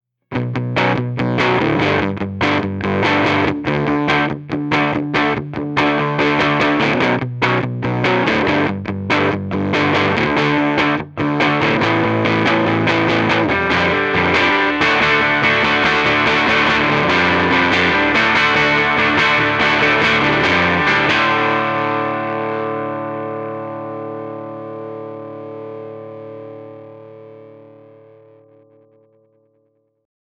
JTM45 Dirty Classic Lead 80
What makes this clip difficult is some speakers work well with the PM's in the first half while others handle the open chord work much better.
JTM_DIRTY_ClassicLead80.mp3